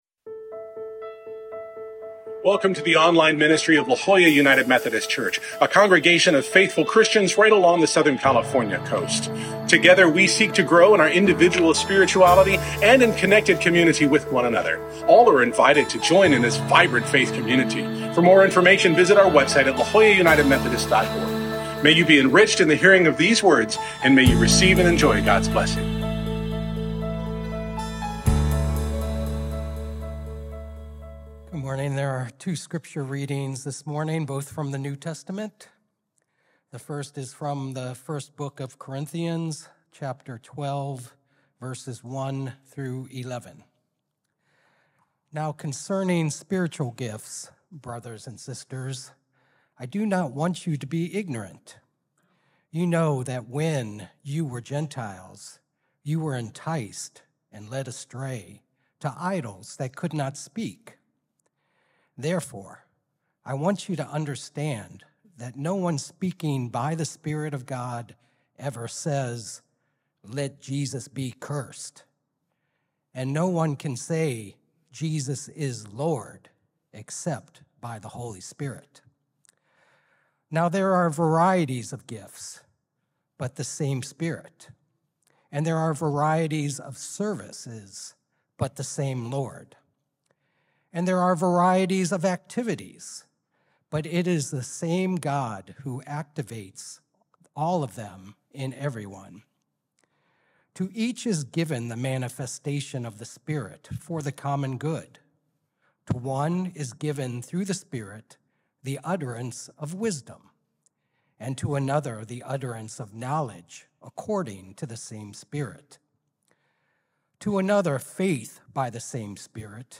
This week, we begin our new sermon series, Foundations and Futures, where we’ll explore how God grounds us in faith and calls us to step boldly into the future. In this first message, we focus on the familiar places and gifts God has provided, inviting us to recognize opportunities to serve and grow within our community.